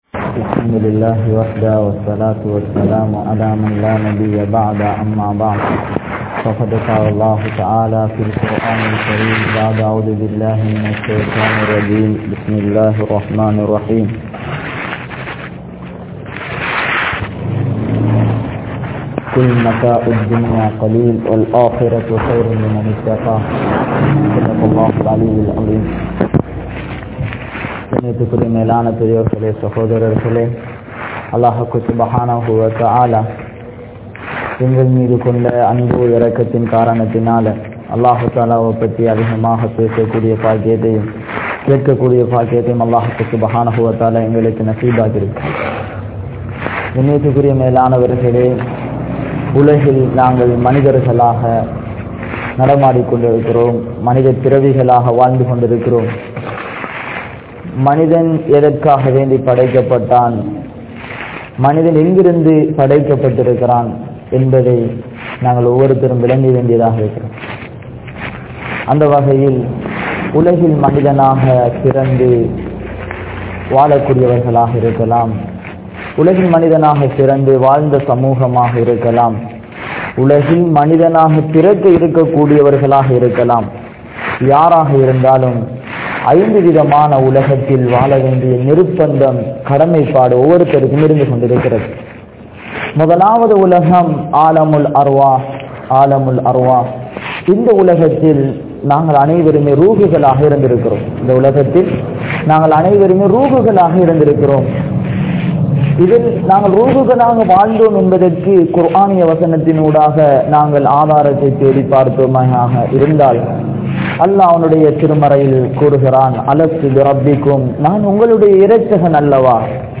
Perumathiyattra Ulaham (பெறுமதியற்ற உலகம்) | Audio Bayans | All Ceylon Muslim Youth Community | Addalaichenai